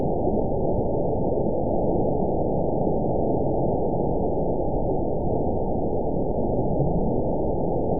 event 921920 date 12/22/24 time 20:44:22 GMT (4 months, 2 weeks ago) score 7.49 location TSS-AB04 detected by nrw target species NRW annotations +NRW Spectrogram: Frequency (kHz) vs. Time (s) audio not available .wav